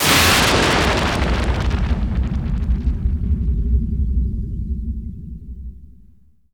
LIGHTENING.wav